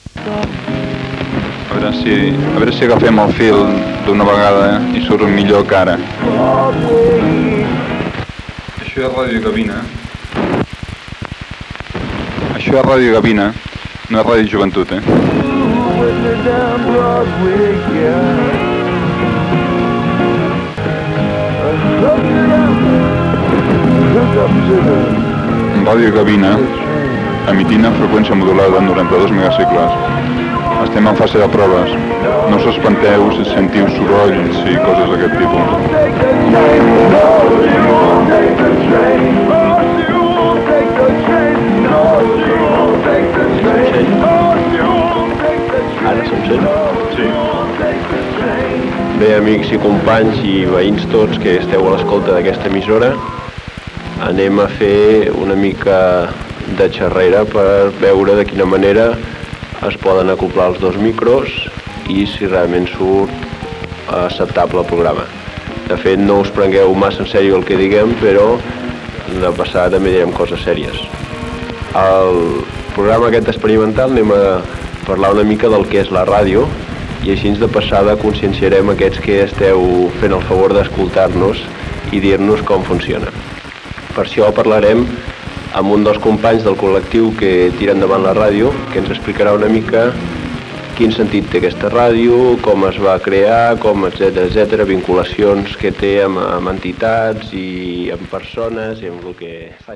26ffc7a48c259559a10a562173dea3047f865a1d.mp3 Títol Ràdio Gavina Emissora Ràdio Gavina Titularitat Tercer sector Tercer sector Lliure Descripció Emissió experimental provant els equips de l'estudi.